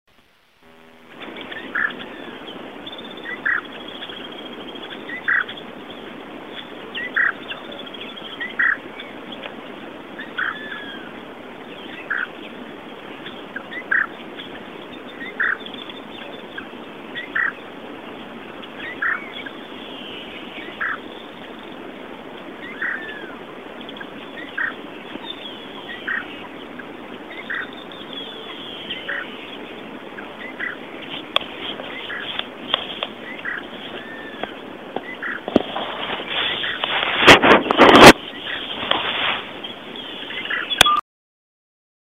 Burrito Negruzco (Laterallus spiloptera)
Vocalización novedosa por su extensión grabada en la provincia de San Juan.
Nombre en inglés: Dot-winged Crake
Localidad o área protegida: Bañados del Caraú
Condición: Silvestre
Certeza: Vocalización Grabada